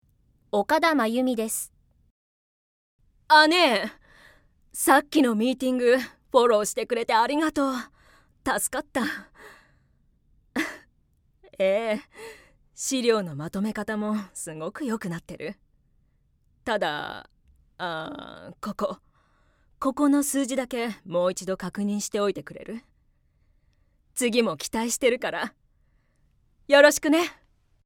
◆外画・上司◆